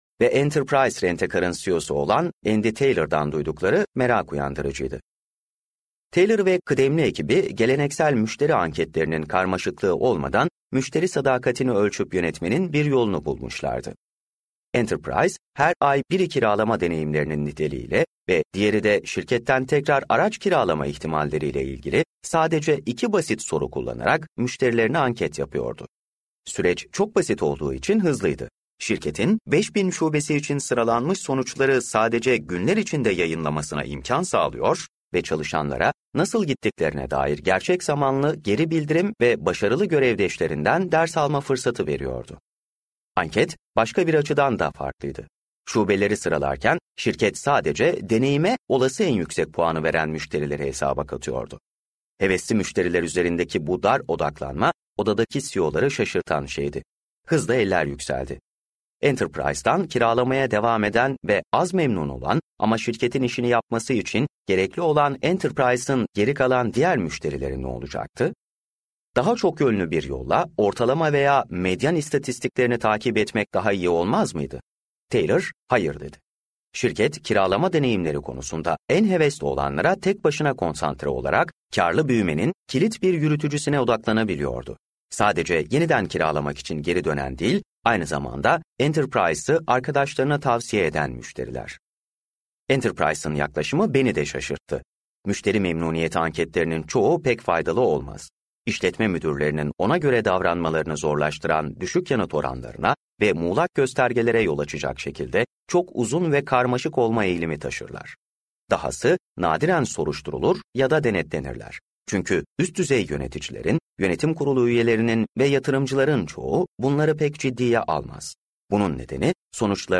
Büyütmeniz Gereken Tek Rakam - Seslenen Kitap